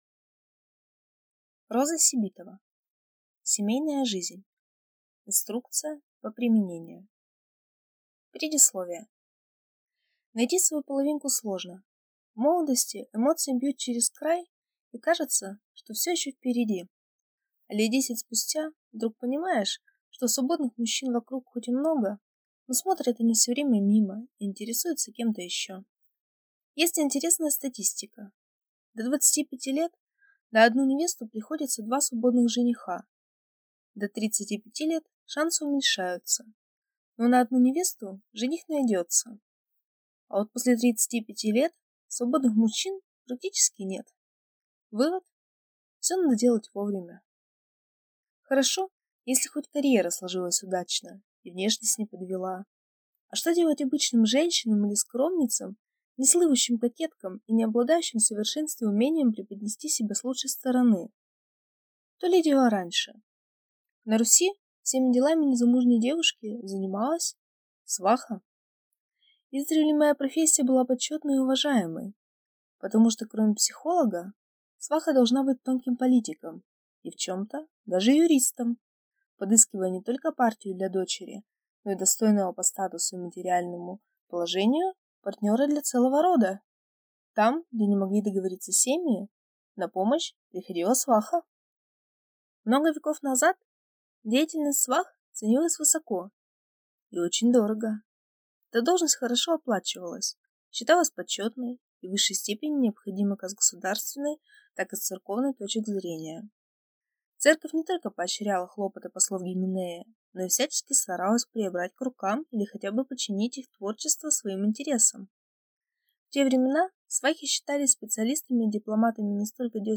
Аудиокнига Семейная жизнь. Инструкция по применению | Библиотека аудиокниг